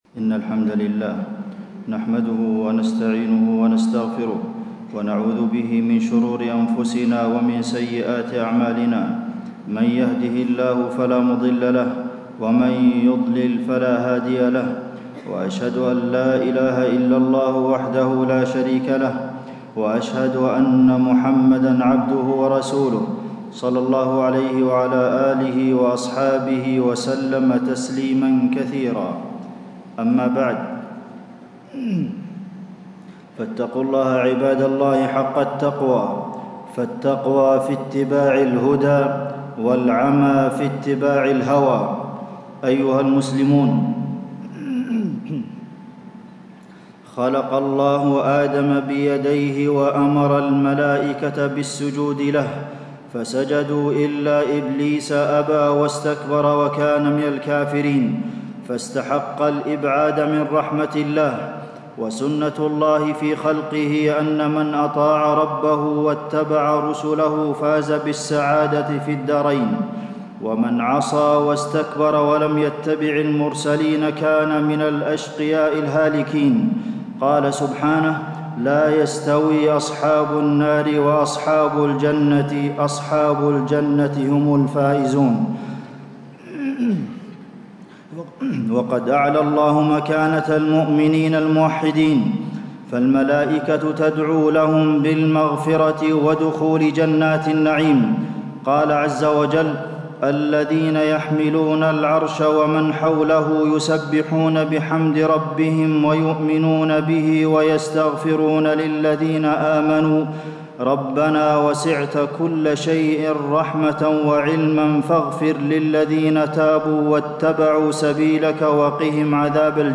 تاريخ النشر ٢٢ شوال ١٤٣٦ هـ المكان: المسجد النبوي الشيخ: فضيلة الشيخ د. عبدالمحسن بن محمد القاسم فضيلة الشيخ د. عبدالمحسن بن محمد القاسم حق المسلم على المسلم